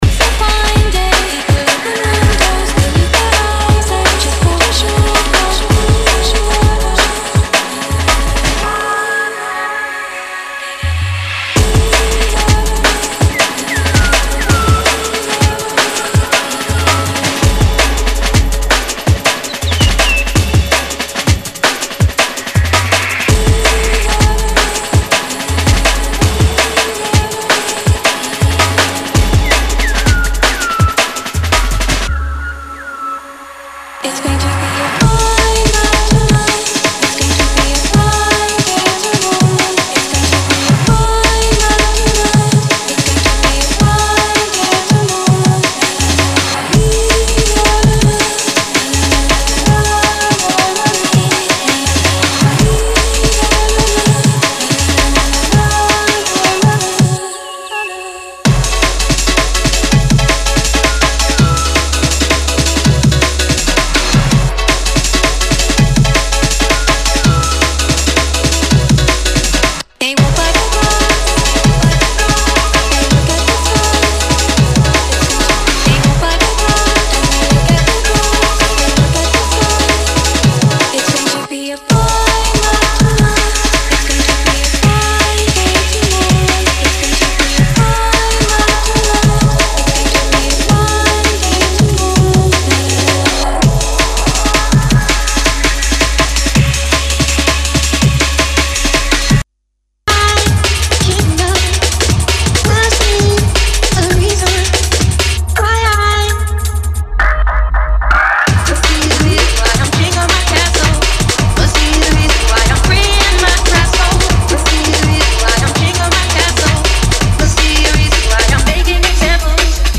Drum´n´bass / Rave Remixes of: